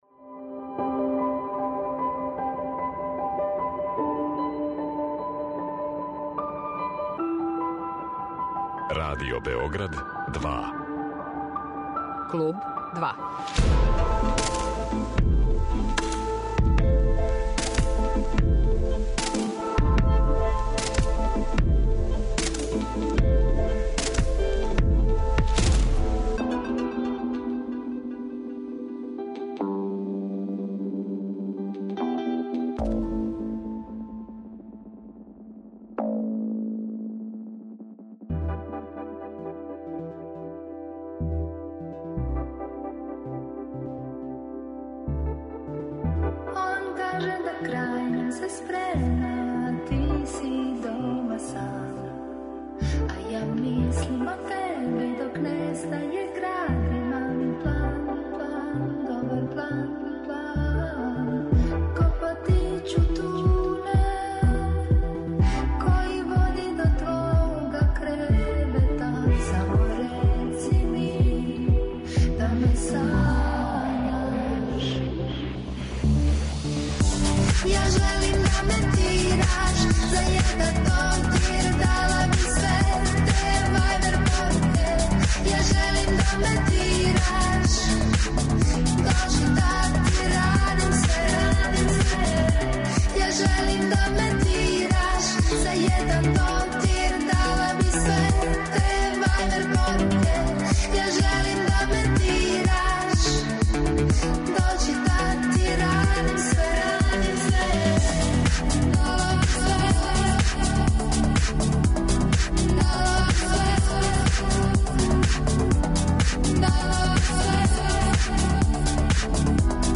Гост емисије биће Ида Престер